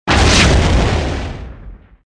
impact_mine.wav